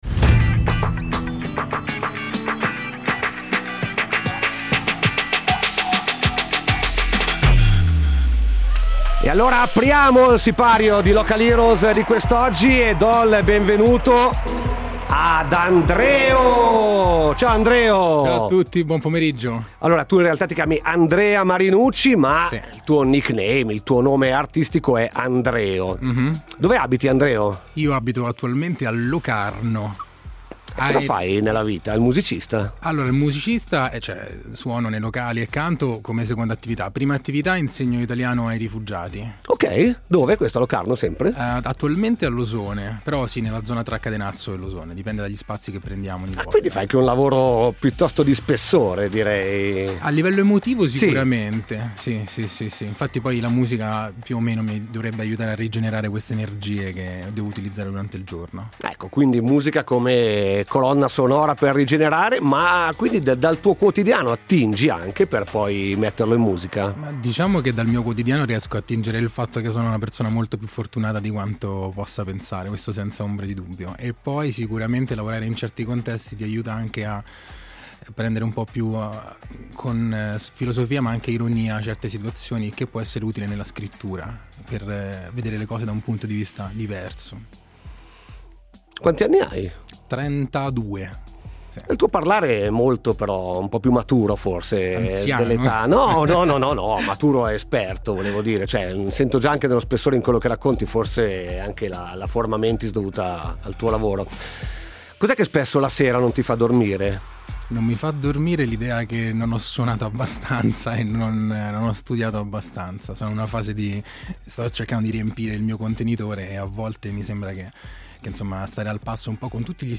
Musica LIVE!